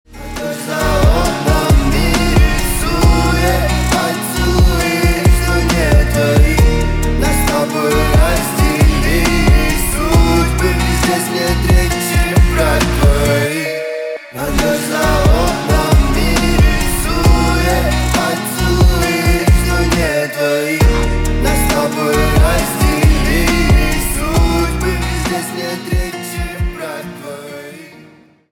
на русском грустные